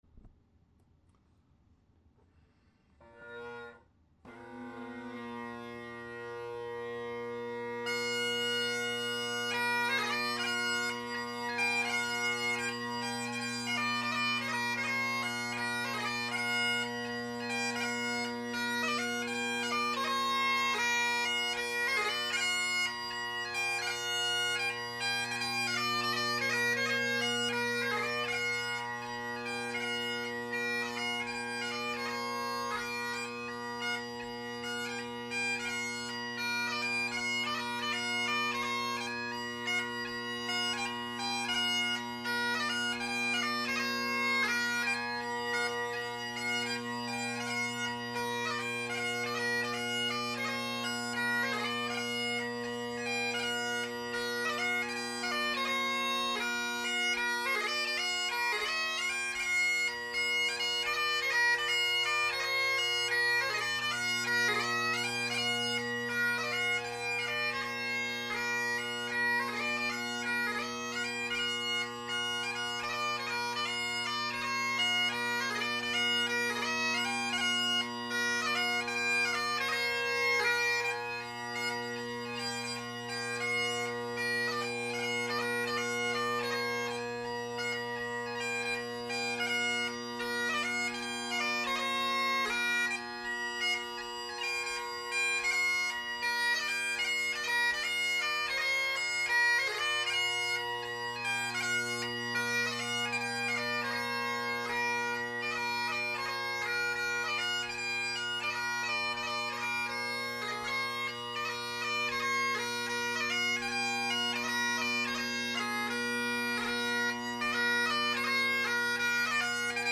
Great Highland Bagpipe Solo
1950’s Hendersons – Selbie drone reeds – Colin Kyo delrin chanter – heavily carved Apps G3 chanter reed
Be patient, each recording is unmodified from the recorder at 160 KB/s using mp3, there are a couple seconds before I strike in after I press record.